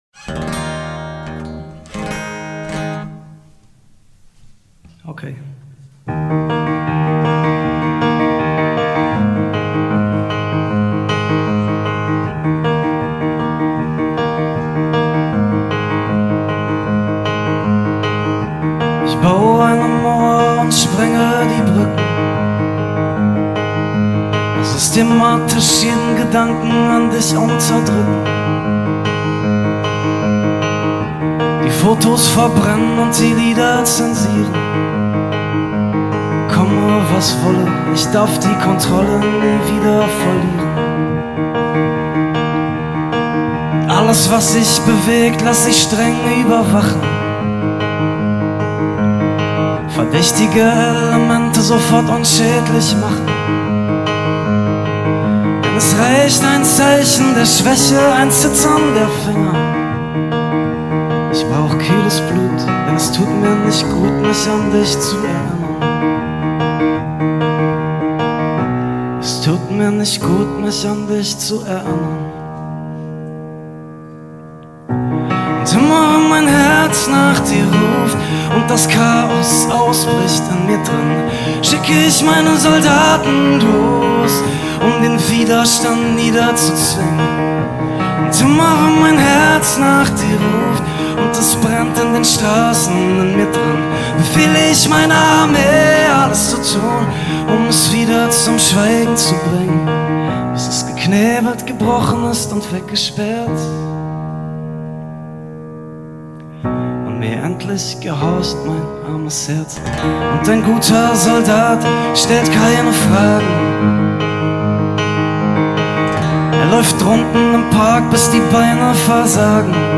Teilnehmerstimme-Audio.mp3